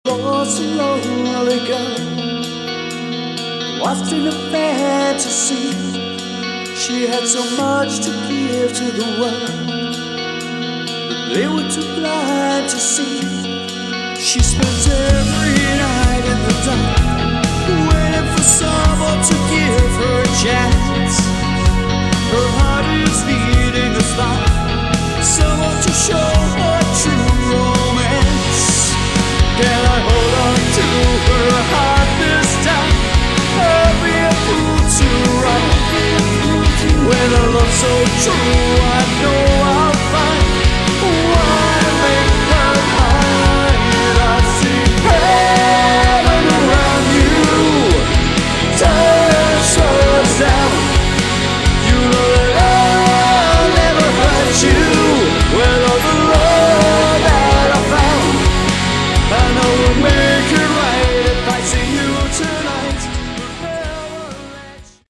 Category: Melodic Rock / AOR
Lead Vocals
Guitar, Vocals
Keyboards, Vocals
Bass Guitar, Vocals